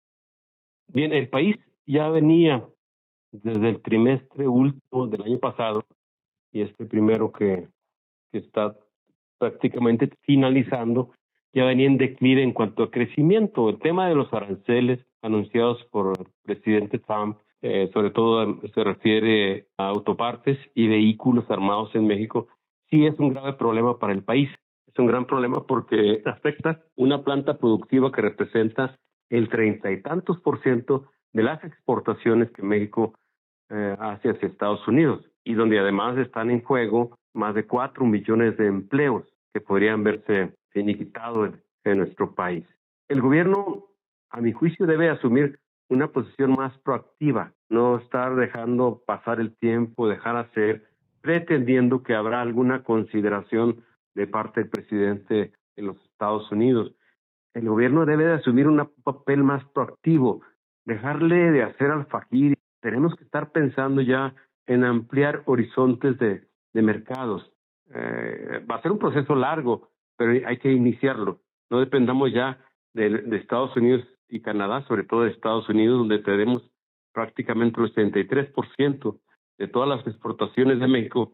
El Senador del PAN por Chihuahua, Mario Vázquez Robles declaró que ante los aranceles impuestos por Donald Trump, el gobierno federal debe asumir una posición más proactiva y ampliar horizontes de mercados.